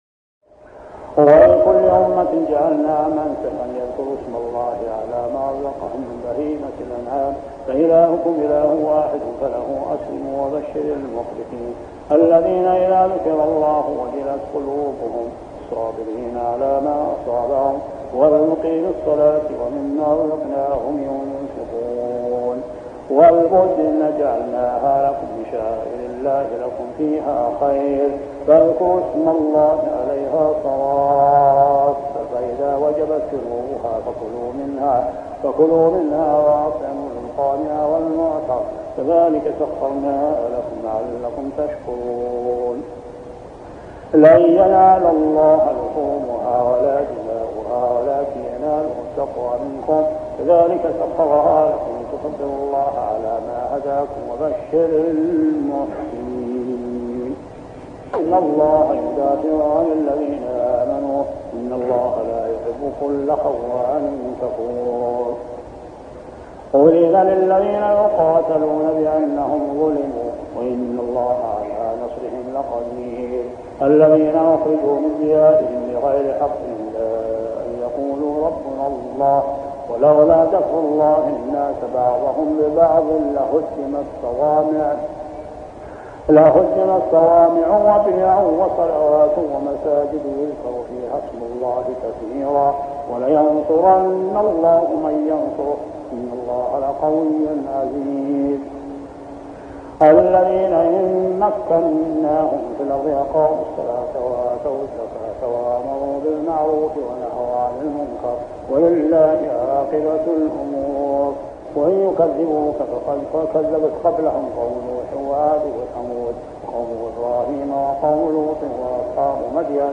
صلاة التراويح عام 1403هـ سورة الحج 34-78 | Tarawih prayer Surah Al-Hajj > تراويح الحرم المكي عام 1403 🕋 > التراويح - تلاوات الحرمين